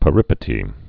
(pə-rĭpĭ-tē)